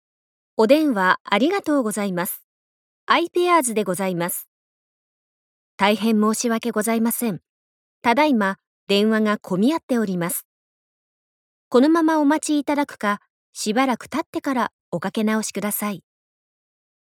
通常版